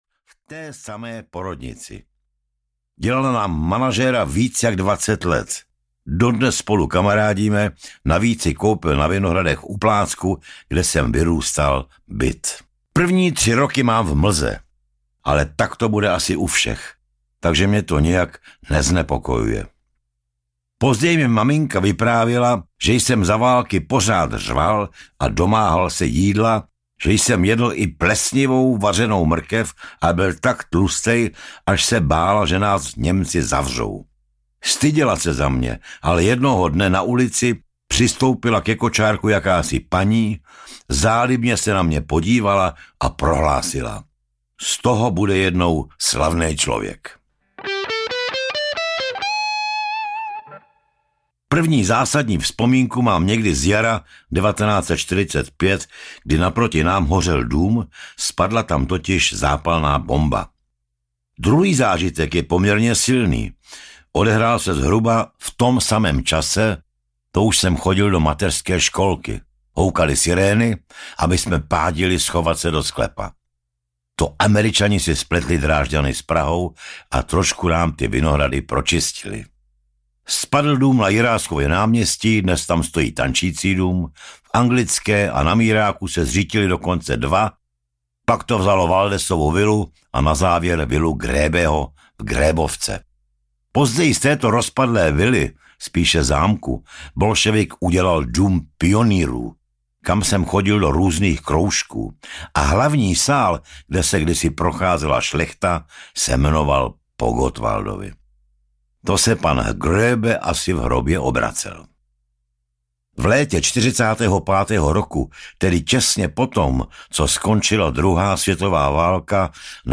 Autorské čtení Petra Jandy.
Ukázka z knihy
Legenda české rockové scény, zpěvák, hudebník, skladatel a frontman kapely Olympic načetl osobně svou autobiografii.Ačkoliv osobu Petra Jandy nelze oddělit od jeho profese, v knize nám odhaluje i své soukromí, poznáváme ho jako člověka, jenž vzpomíná na dětství, rodiče i svoji vlastní rodinu, na hezké i tragické chvíle, zkrátka na vše, co mu život v průběhu času dal i vzal.
Navíc Petr Janda své vyprávění proložil vlastními kytarovými improvizacemi.